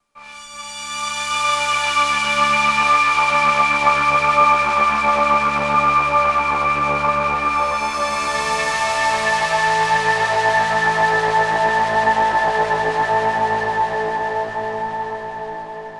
Novation Peak – Klangbeispiele
novation_peak_test__sweep_pad.mp3